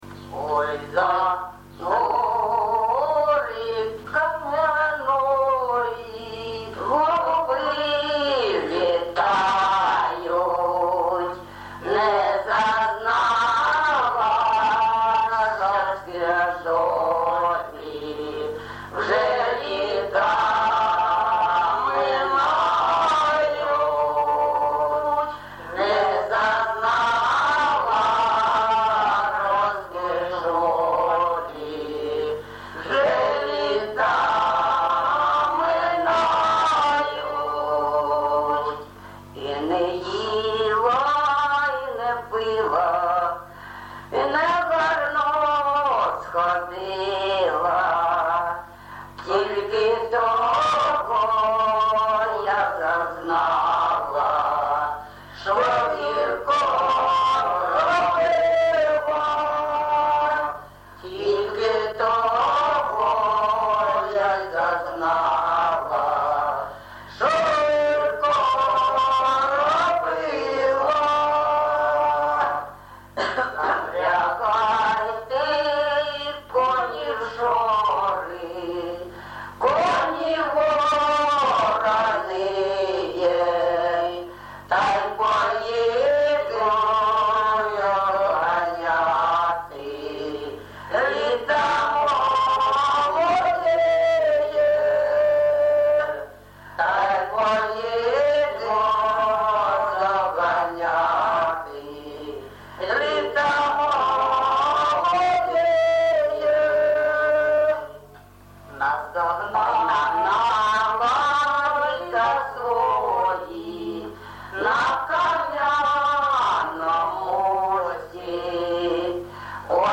ЖанрПісні з особистого та родинного життя
МотивНещаслива доля, Журба, туга
Місце записум. Єнакієве, Горлівський район, Донецька обл., Україна, Слобожанщина